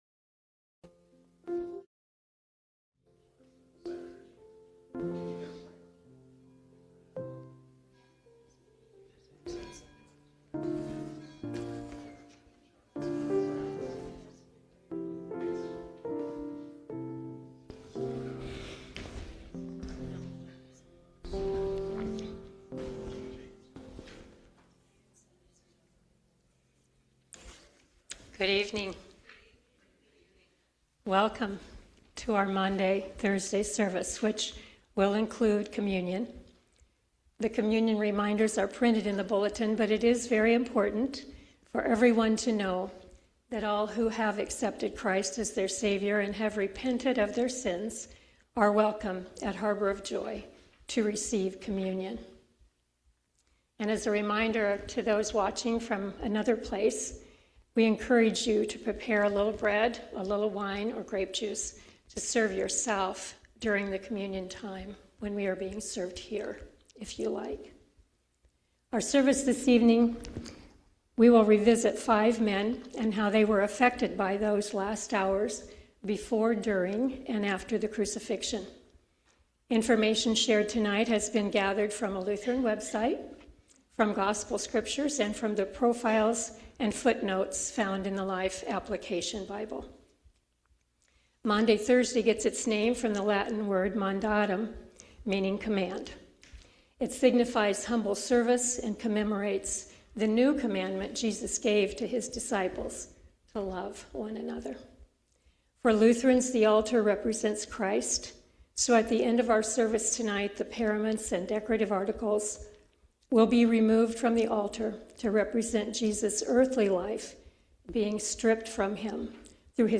Maundy Thursday Service
Worship-Maundy-Thursday-April-2-2026-Voice-Only.mp3